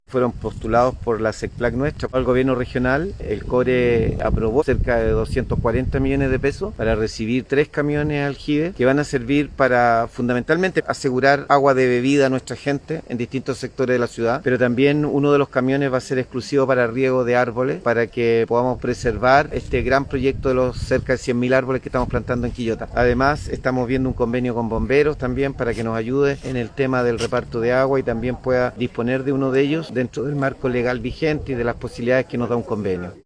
01-ALCALDE-Tres-nuevos-aljibes.mp3